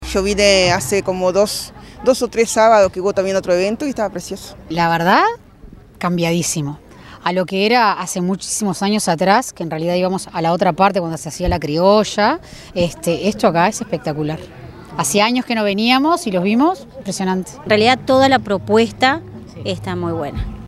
vecinas.mp3